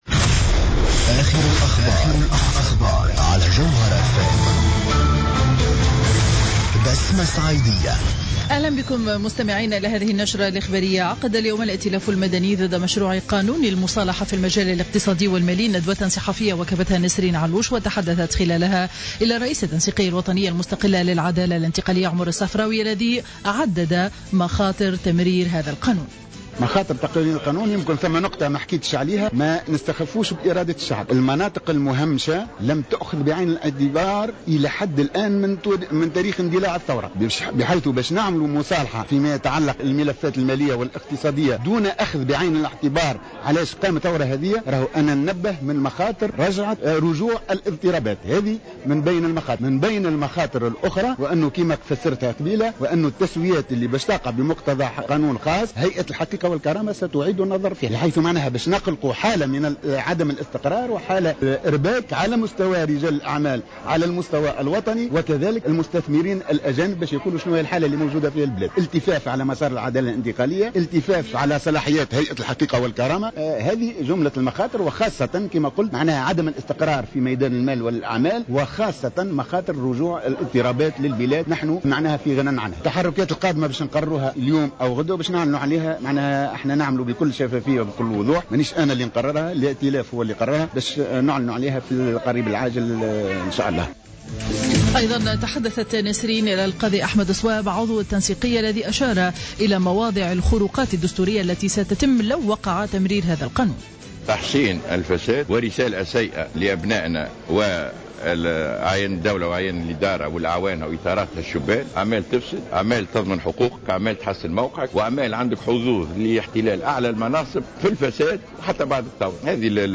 نشرة أخبار منتصف النهار ليوم الخميس 27 أوت 2015